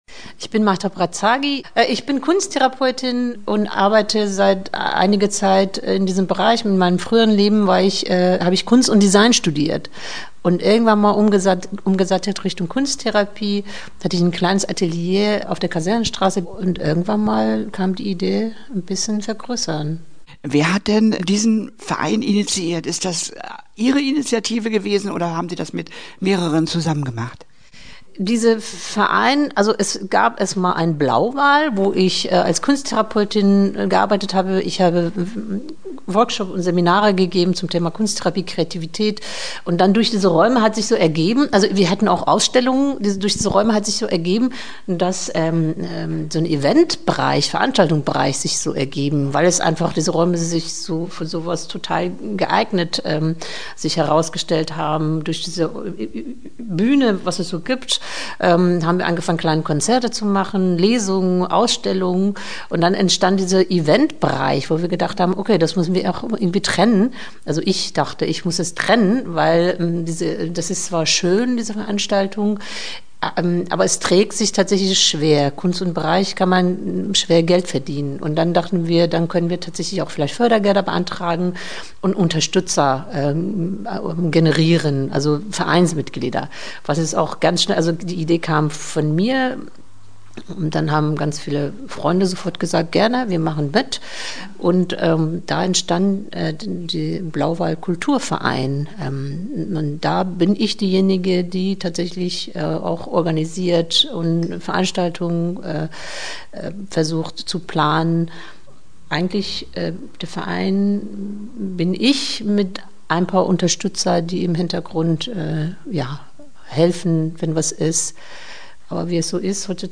Interview-Blau-Wal.mp3